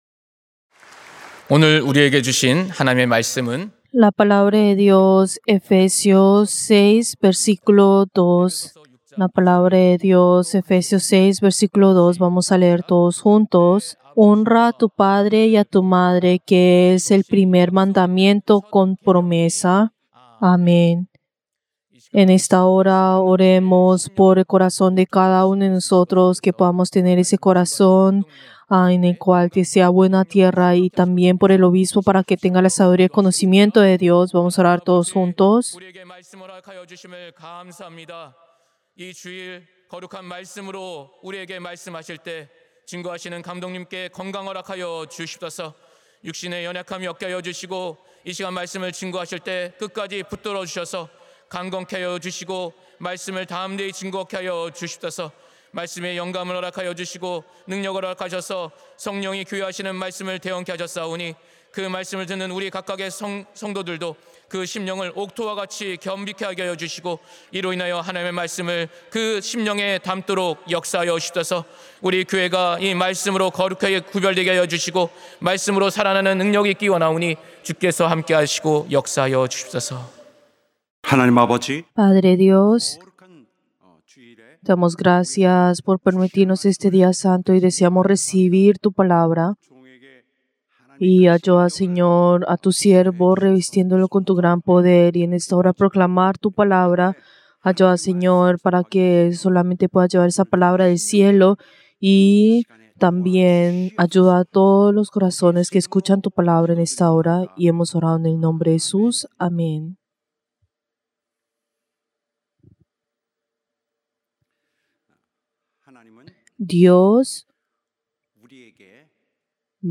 Servicio del Día del Señor del 27 de abril del 2025